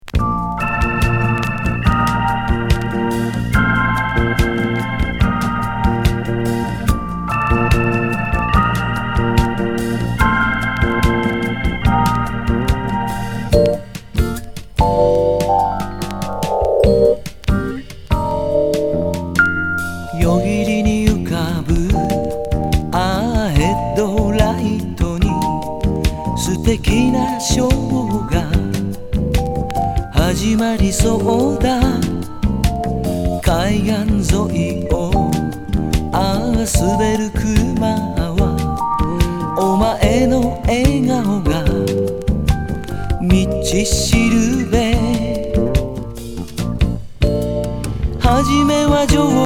エゲツないスペース・シンセ入りディスコ歌謡A面